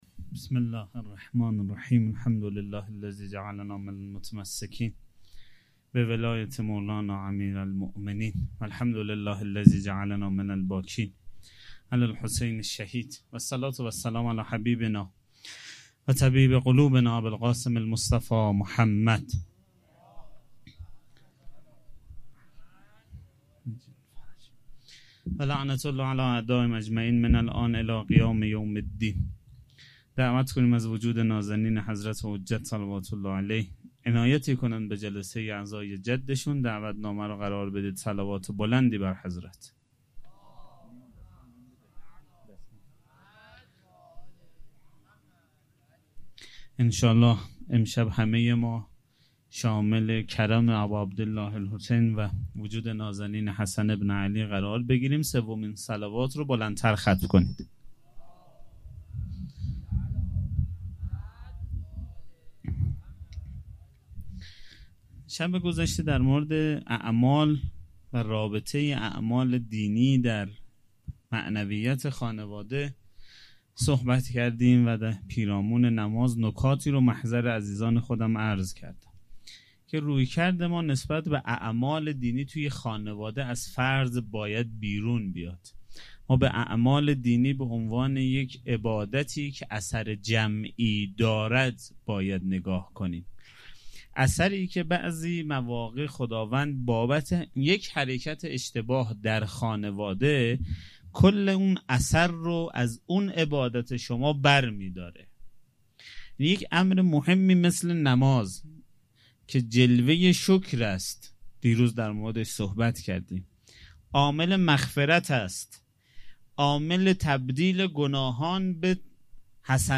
سخنرانی شب پنجم